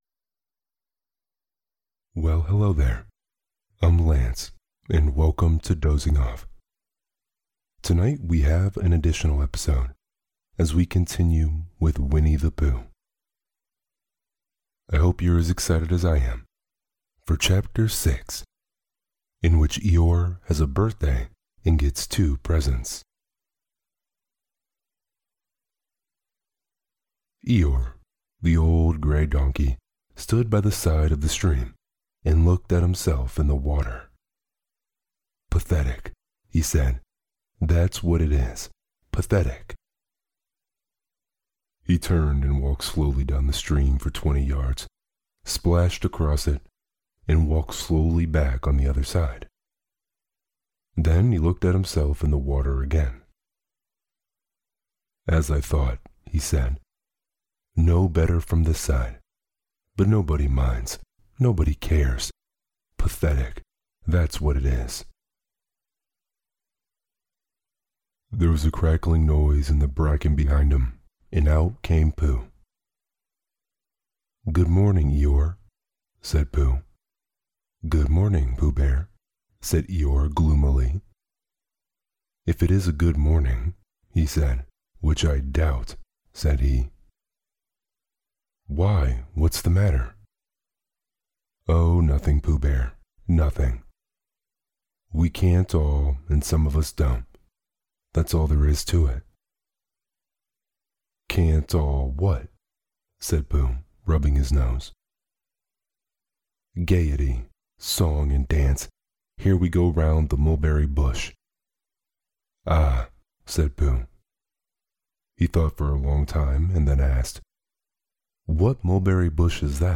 Peter Pan - J.M Barrie (Part Two) – Dozing Off | Deep Voice ASMR Bedtime Stories – Podcast